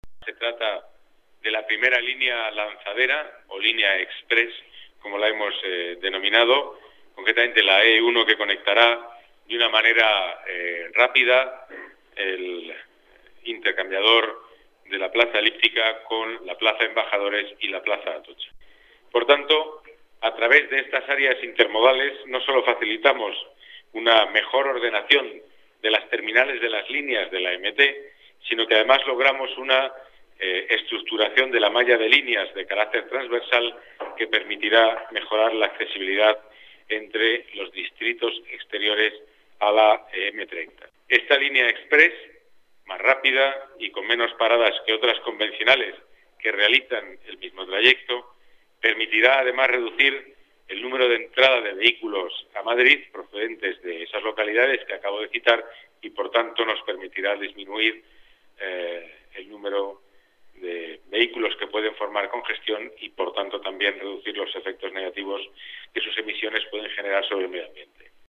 Nueva ventana:Declaraciones del delegado de Movilidad y Seguridad, Pedro Calvo, sobre la rápidez de conexión de la lanzadera